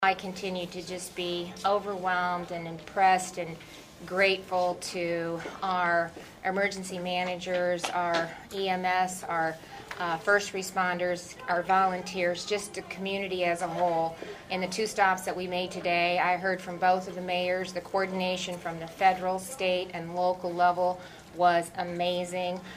REYNOLDS SAYS SHE WAS IMPRESSED BY THE QUICK RESPONSE TO HELP RESIDENTS OF THE FLOOD STRICKEN COMMUNITIES: